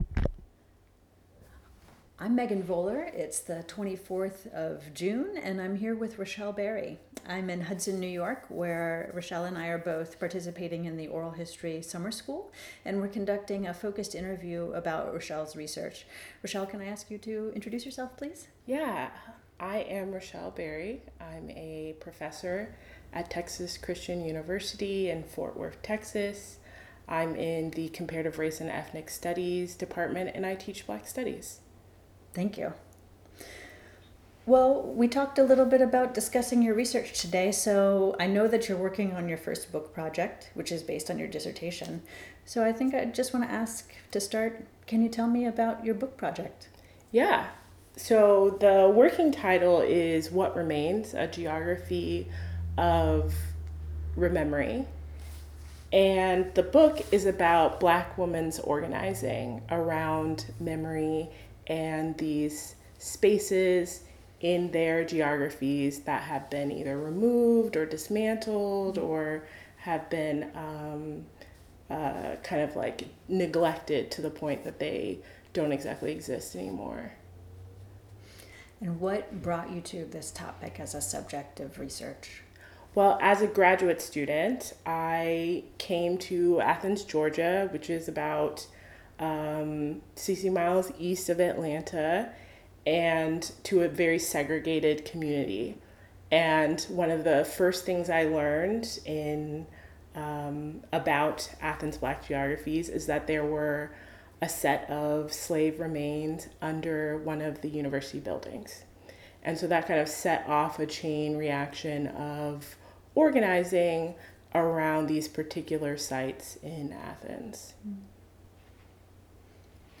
In this focused interview